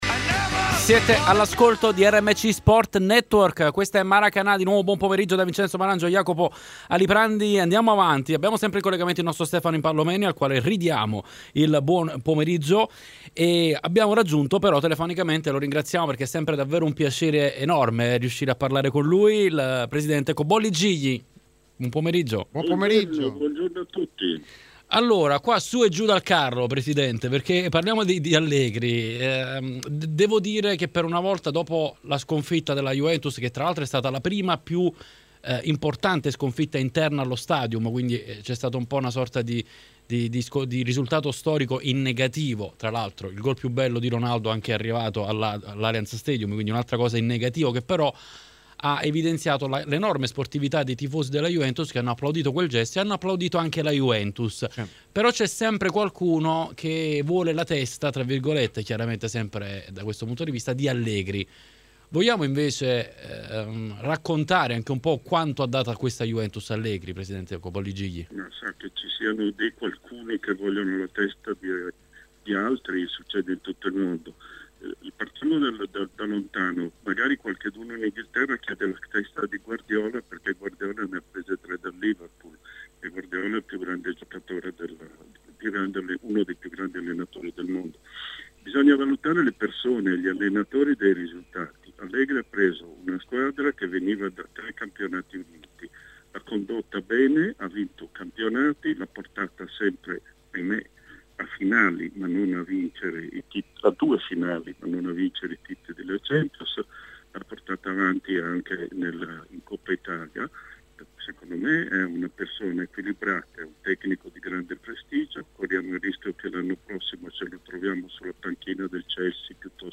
Ospite di Maracanà, trasmissione pomeridiana di RMC Sport, Giovanni Cobolli Gigli, ex presidente della Juventus, ha commentato questa due giorni di Champions League: